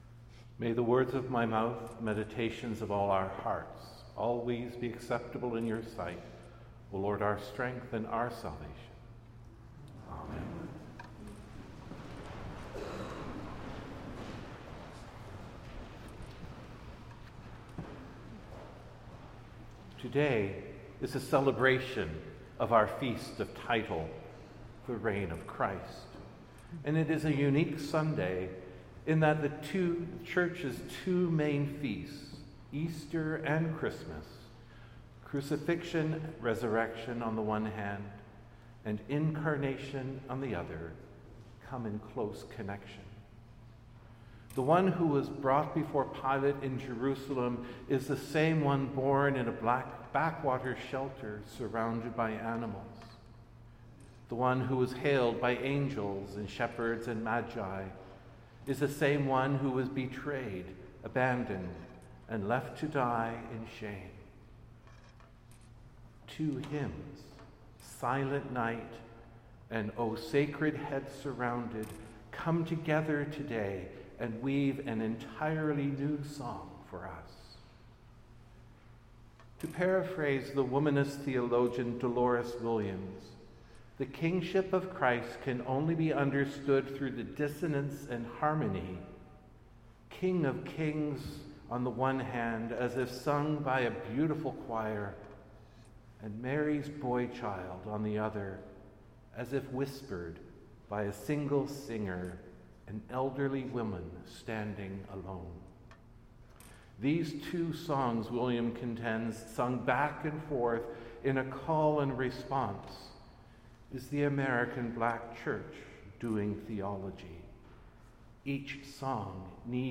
Sermons | Christ Church Cathedral Ottawa